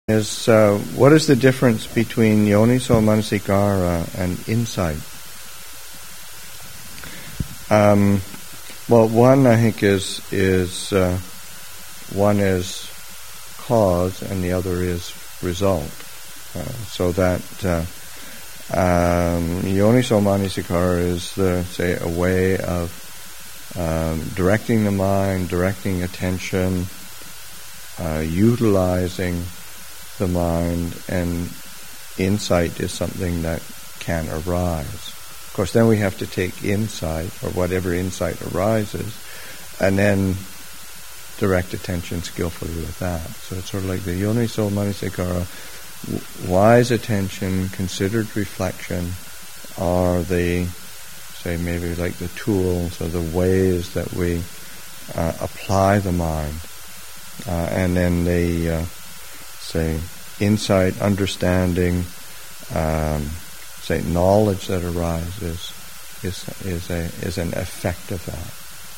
Thanksgiving Retreat 2016, Session 1, Excerpt 3